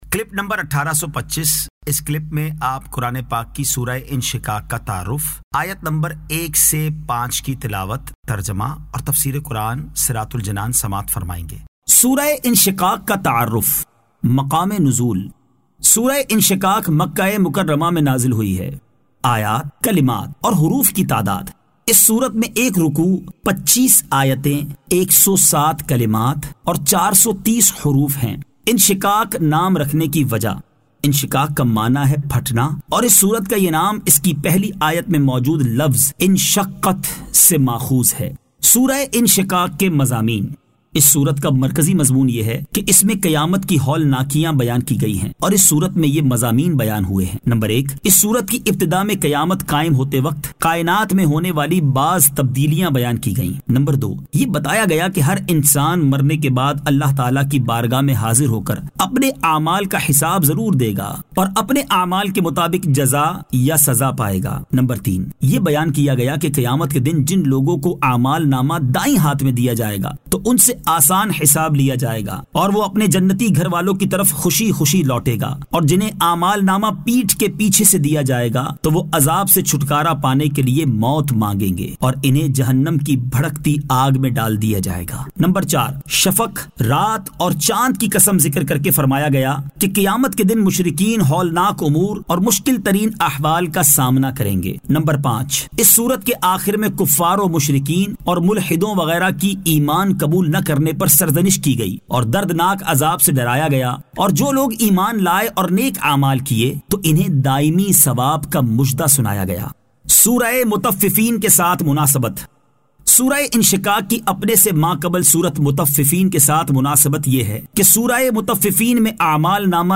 Surah Al-Inshiqaq 01 To 05 Tilawat , Tarjama , Tafseer